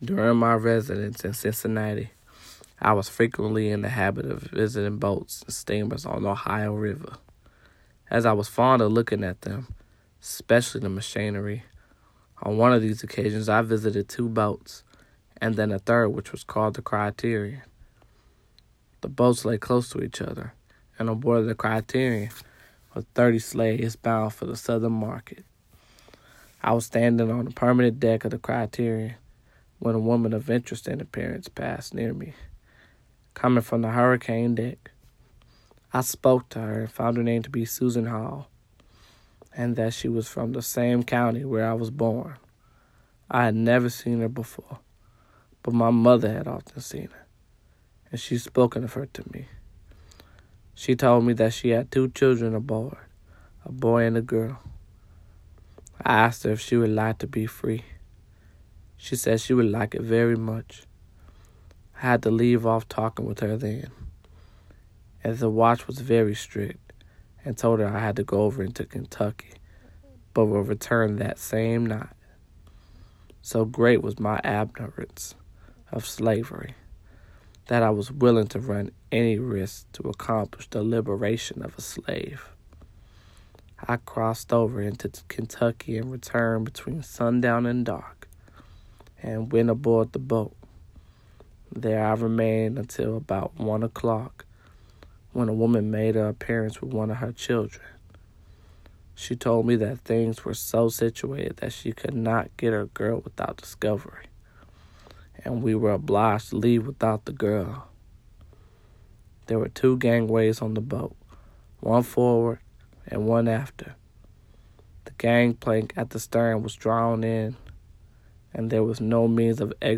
Listen to a reenactment of an excerpt from The Autobiography of John Malvinwhich recounts the story of how he helped free slaves from a boat.